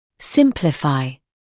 [‘sɪmplɪfaɪ][‘симплифай]упрощать